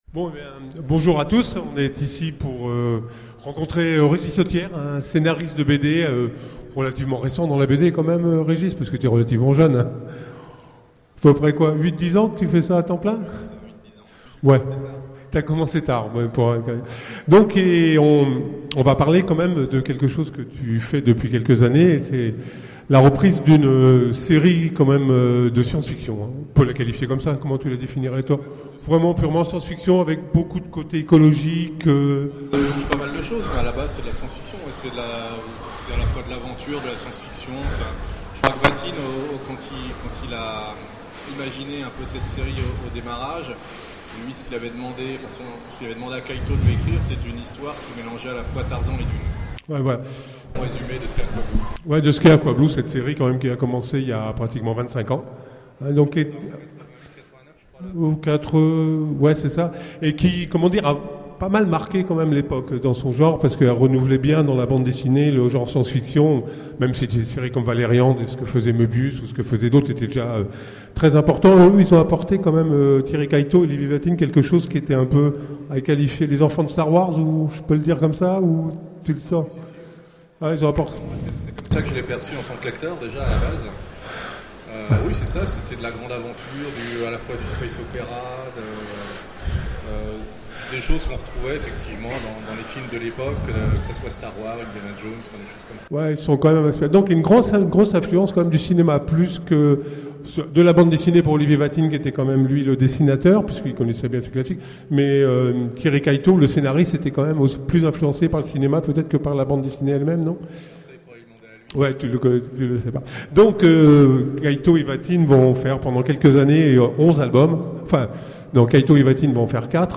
Utopiales 13 : Conférence Autour d'Aquablue
Mots-clés Rencontre avec un auteur Conférence Partager cet article